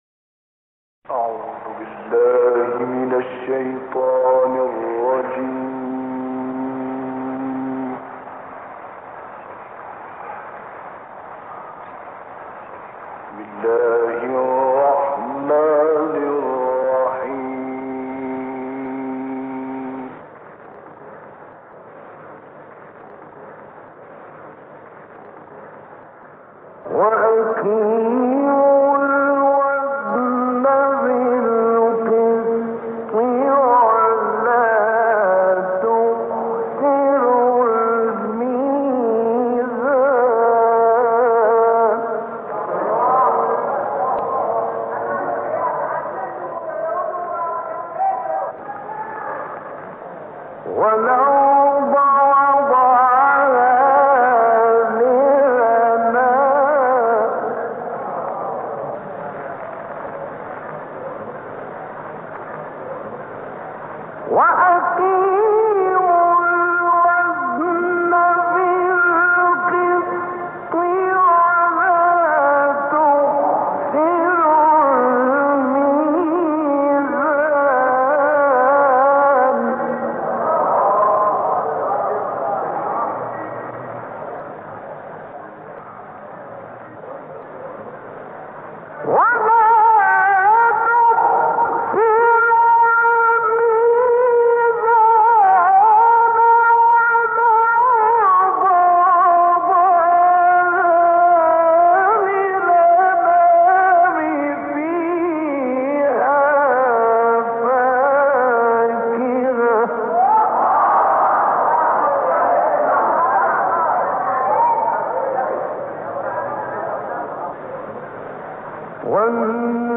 تلاوت آیات ۹ تا ۳۳ سوره الرحمن و آیات ۱ تا ۲۴ سوره حاقه را با صوت راغب مصطفی غلوش، قاری بنام مصری می‌شنوید. این تلاوت در سال ۱۹۶۷ میلادی در مسجد امام حسین (ع) شهر قاهره، اجرا شده است.